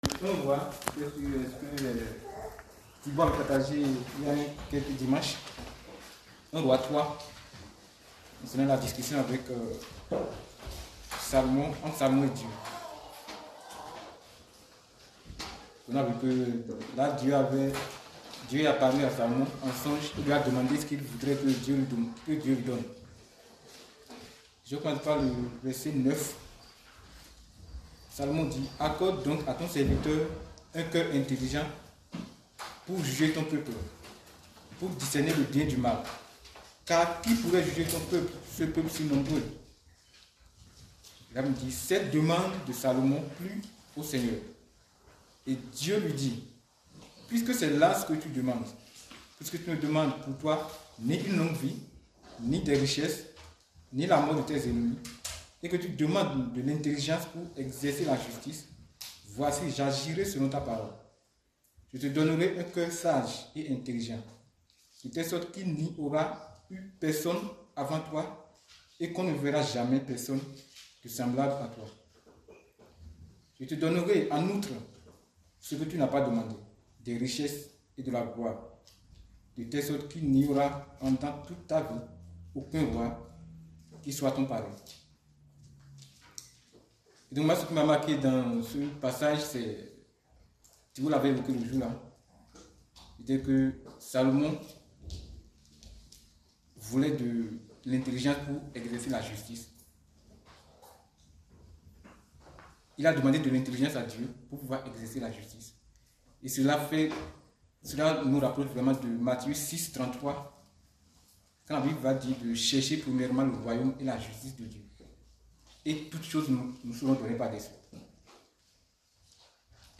Exhortation à rechercher la justice puis quelques pensées qui seront partagées lors d’un prochain voyage missionnaire (combat spirituel, communion avec nos frères et soeurs …)